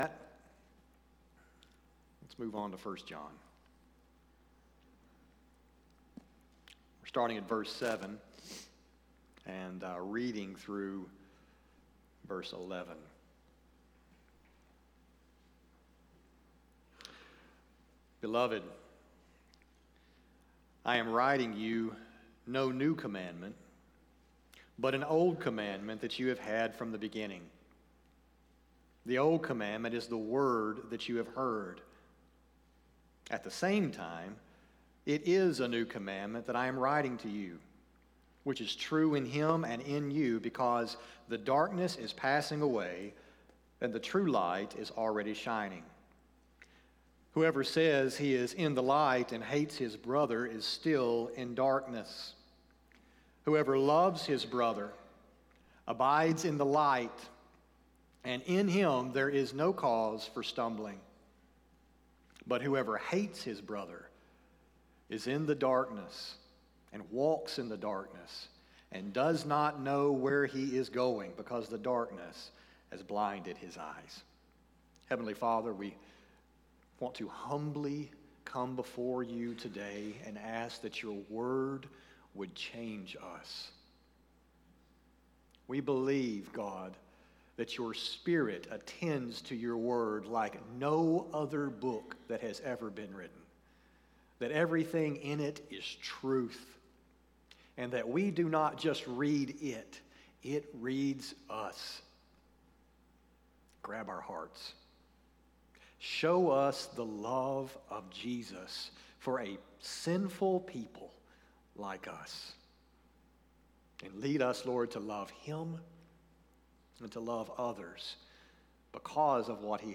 sermon-2-2-20.mp3